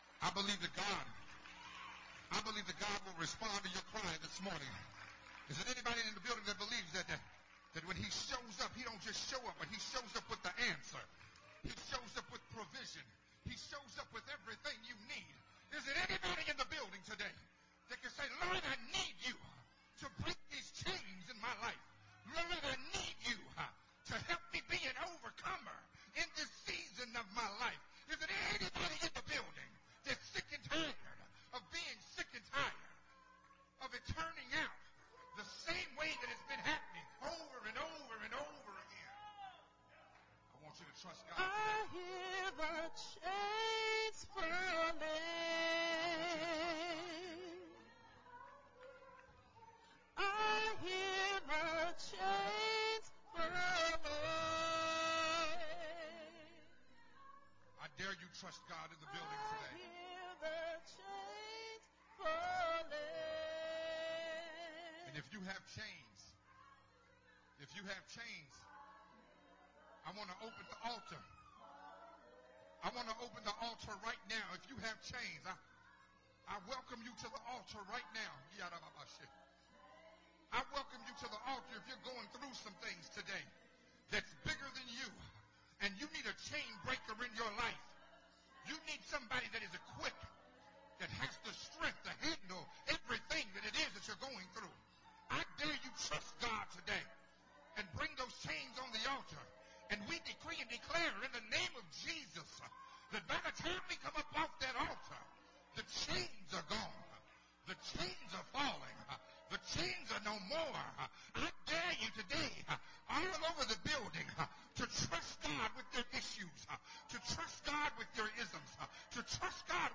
the final episode of the sermon series
recorded at Unity Worship Center